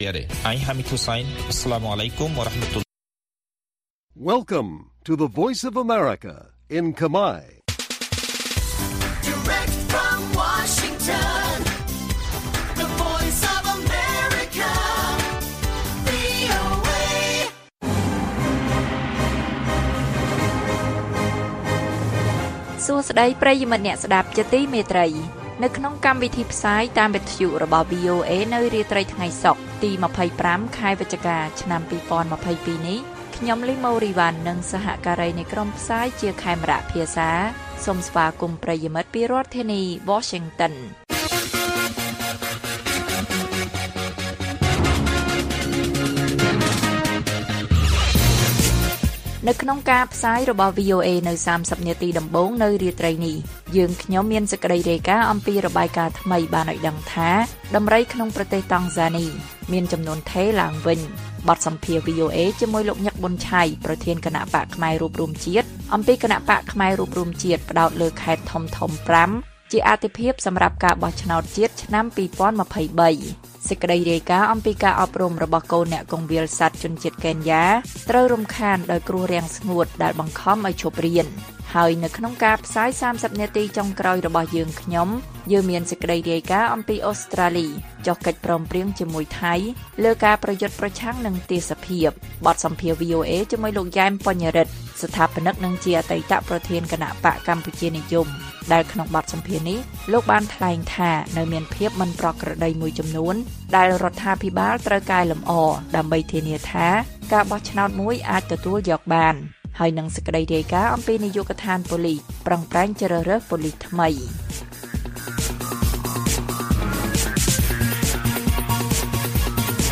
ព័ត៌មានពេលរាត្រី ២៥ វិច្ឆិកា៖ គណបក្សខ្មែររួបរួមជាតិផ្តោតលើខេត្តធំៗ៥ ជាអាទិភាពសម្រាប់ការបោះឆ្នោតជាតិឆ្នាំ២០២៣